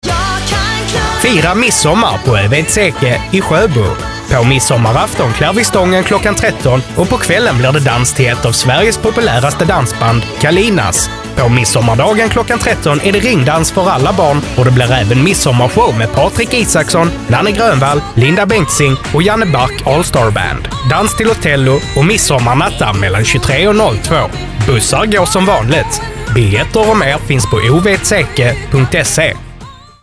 Här kommer årets radioreklam klicka här eller på bilden